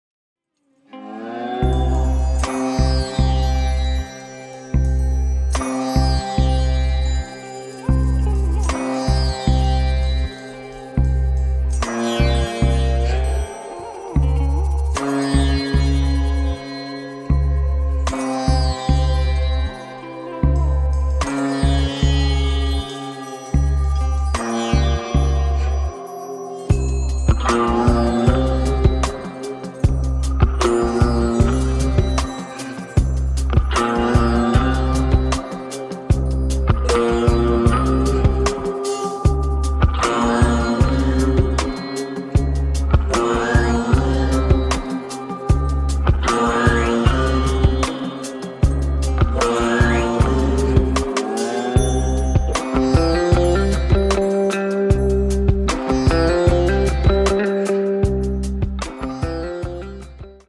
Killer eastern influenced instrumental goodness